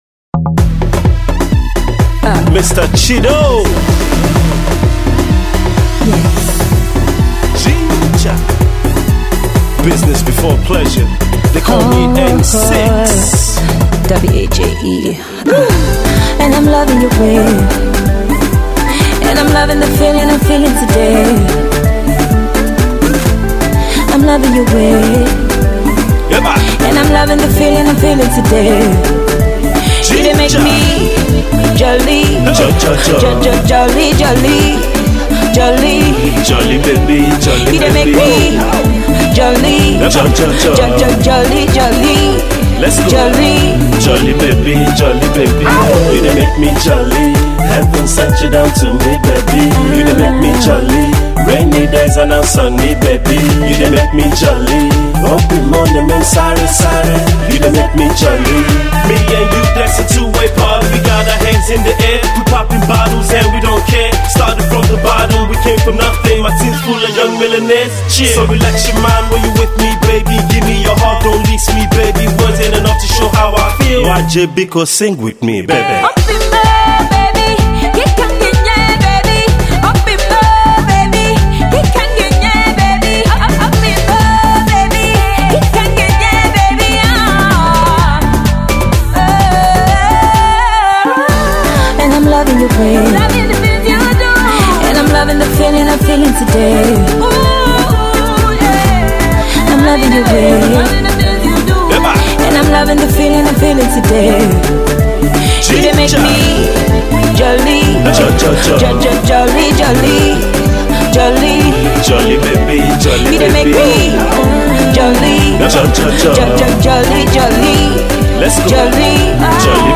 Afro-Soul diva